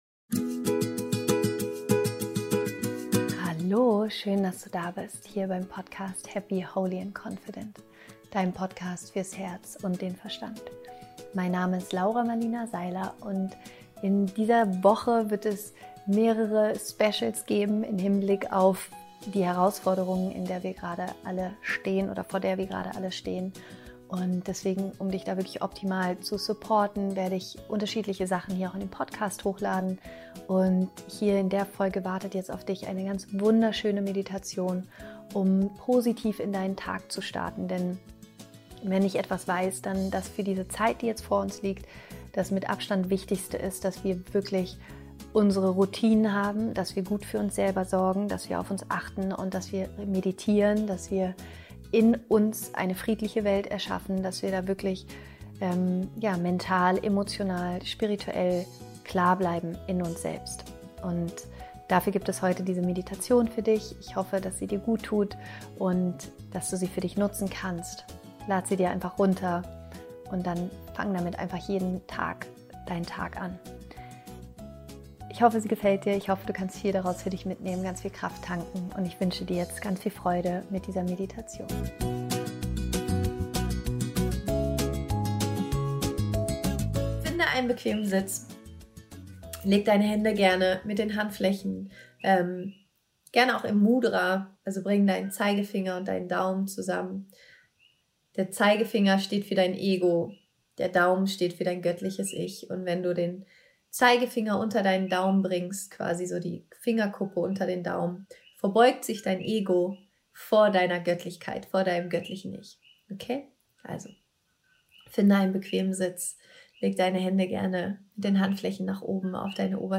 Special: Morgenmeditation, um positiv in den Tag zu starten